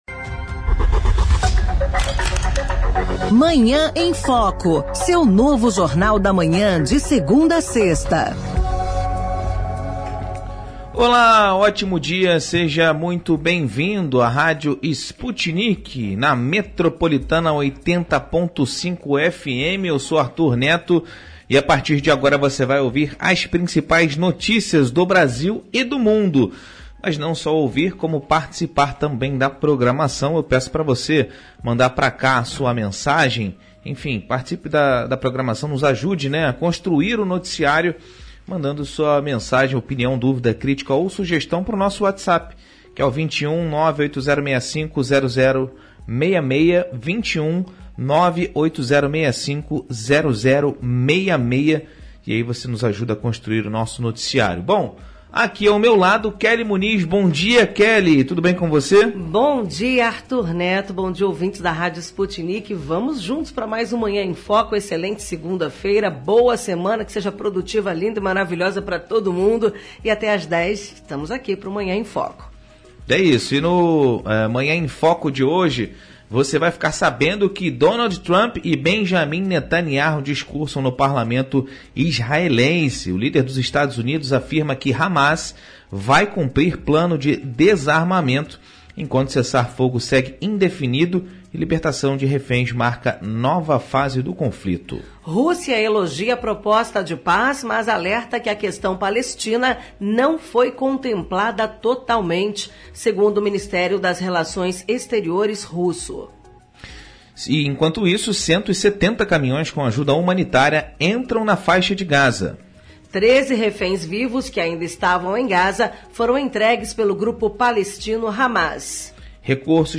concede entrevista ao vivo no programa Manhã em Foco, da Rádio Sputnik Brasil.